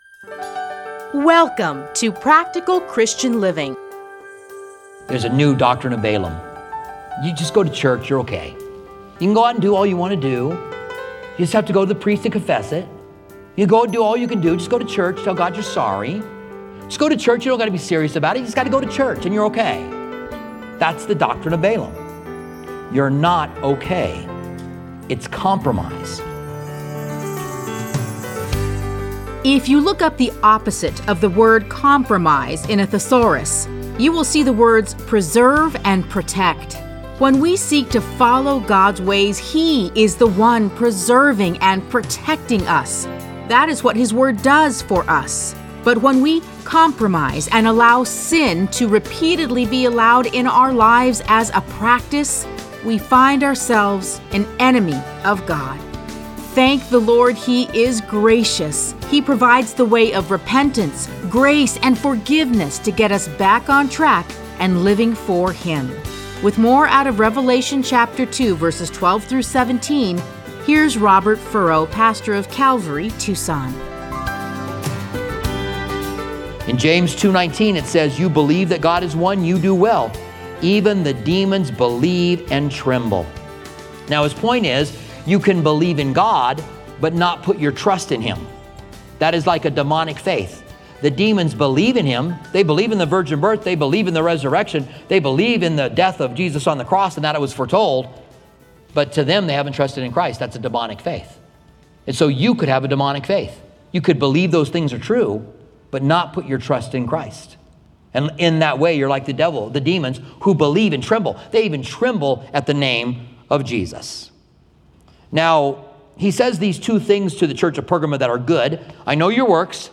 Listen to a teaching from Revelation 2:12-17.